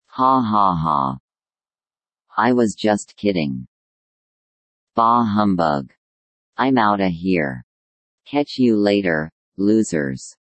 Play, download and share 7 Ha ha ha original sound button!!!!
7-ha-ha-ha.mp3